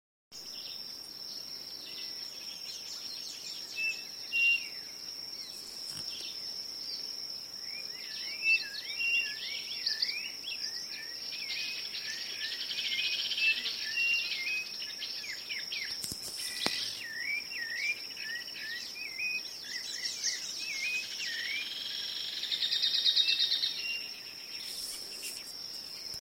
Striped Cuckoo (Tapera naevia)
Life Stage: Adult
Location or protected area: Reserva Ecologica Los Corbalanes
Condition: Wild
Certainty: Observed, Recorded vocal